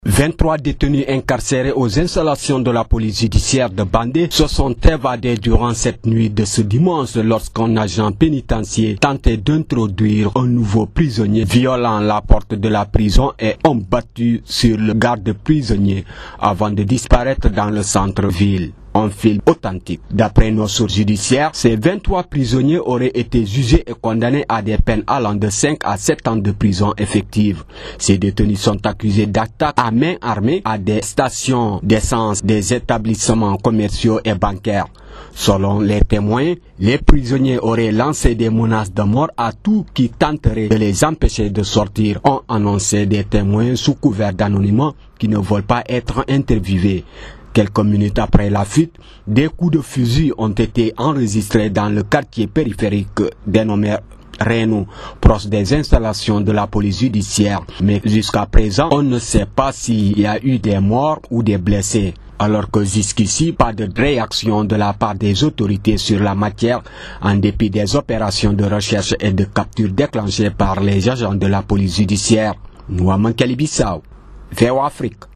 Reportage
à la prison de Koutoukalé, à 50 km au nord-ouest de Niamey